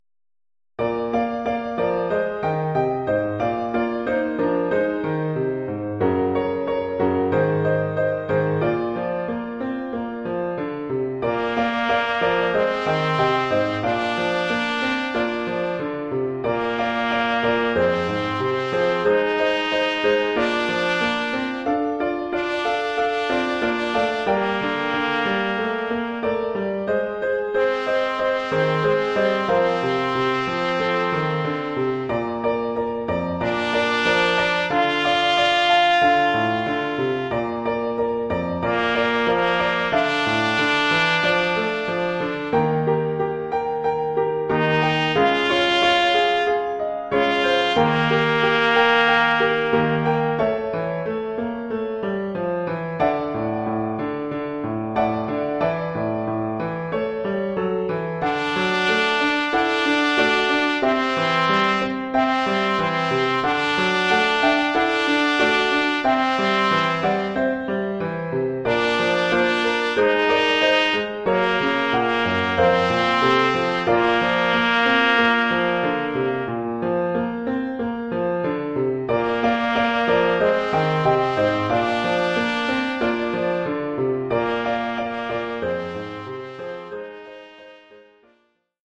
Oeuvre pour trompette ou cornet
ou bugle et piano.
Niveau : 1er cycle, débutant.